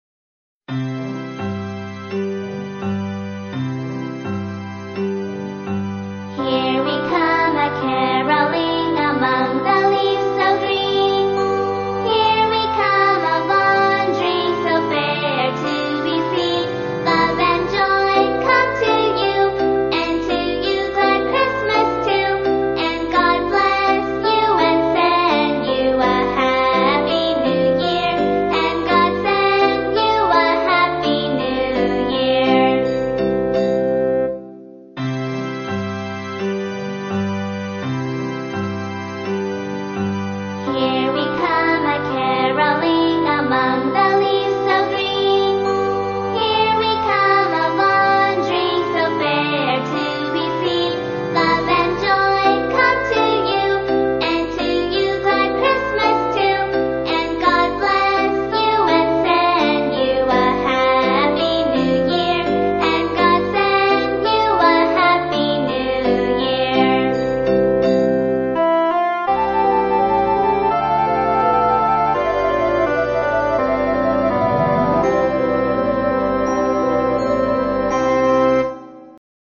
在线英语听力室英语儿歌274首 第61期:Here We Come A-Caroling的听力文件下载,收录了274首发音地道纯正，音乐节奏活泼动人的英文儿歌，从小培养对英语的爱好，为以后萌娃学习更多的英语知识，打下坚实的基础。